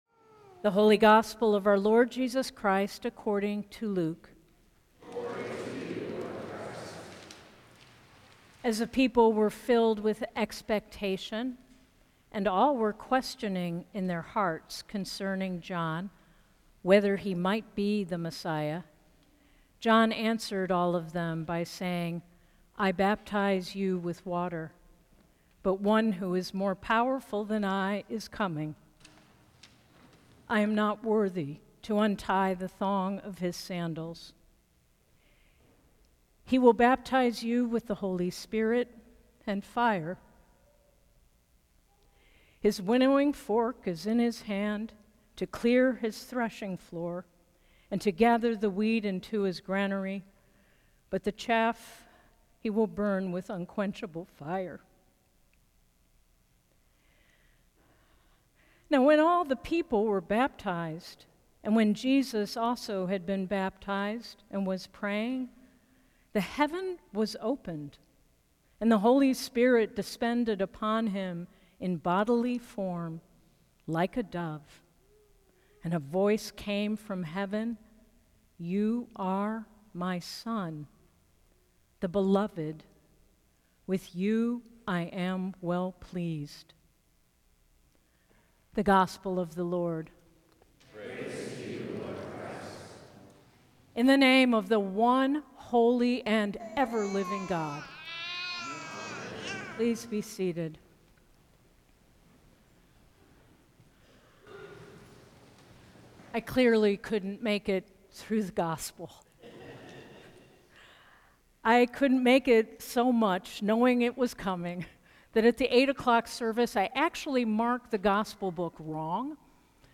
St. Augustine by-the-Sea Sermons